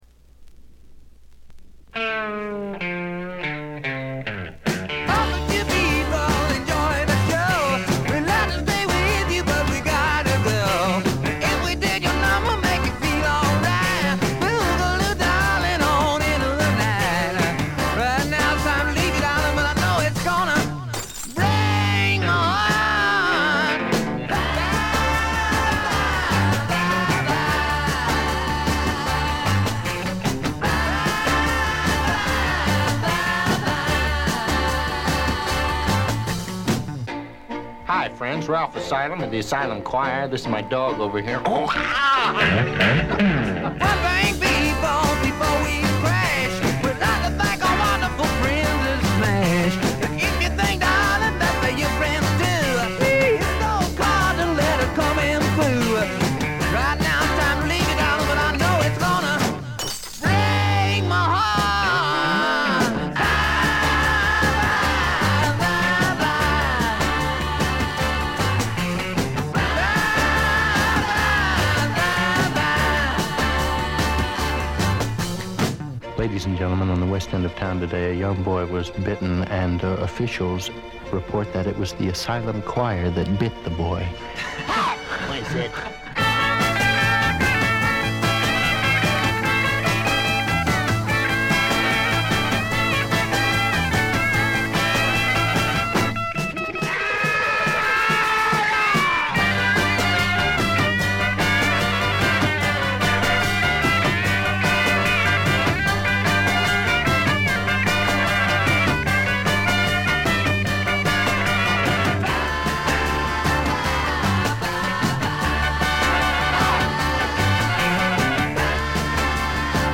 軽微なバックグラウンドノイズ、少しチリプチ。
60年代ポップ・サイケな色彩でいろどられたサージェント・ペパーズな名作！！
試聴曲は現品からの取り込み音源です。